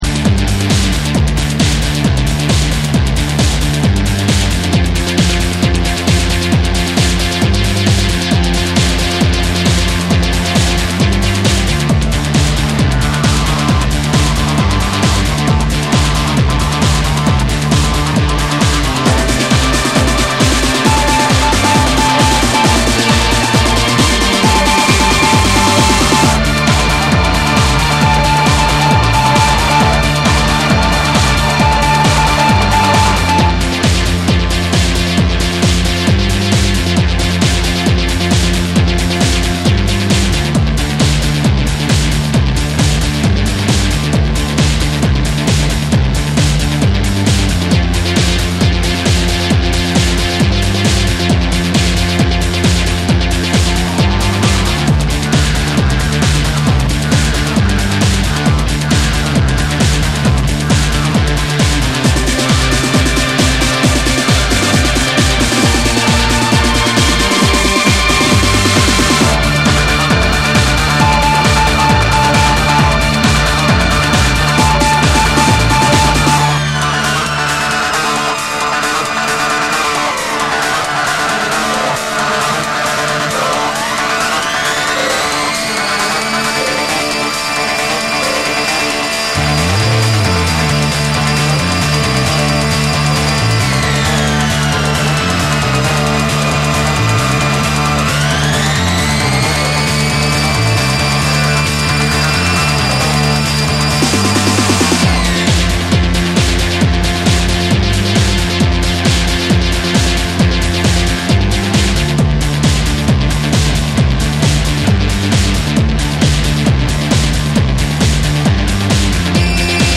NEW WAVE & ROCK / TECHNO & HOUSE